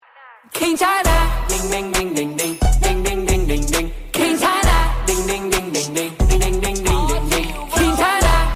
gwenchana ding ding ding sound sound effects
gwenchana-ding-ding-ding-sound